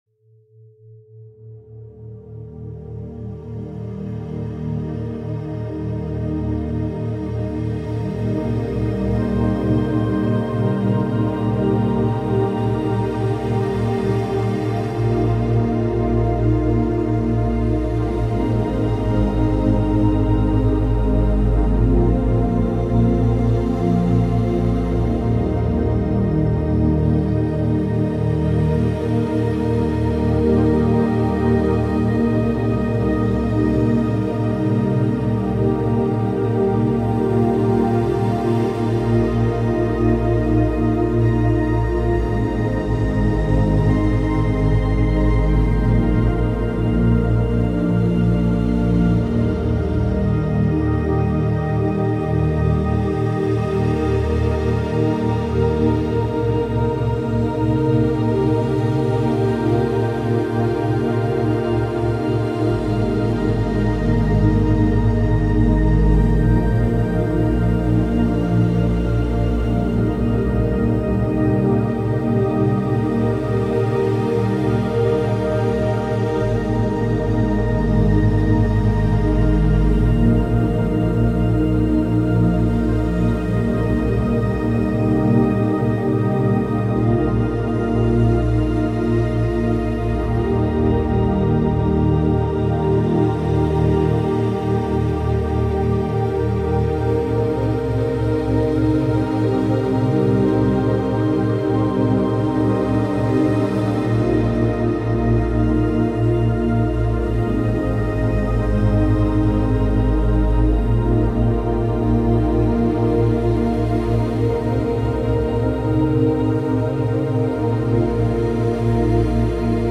Subconscious Meditation – Mind and Body Alignment with Binaural Beats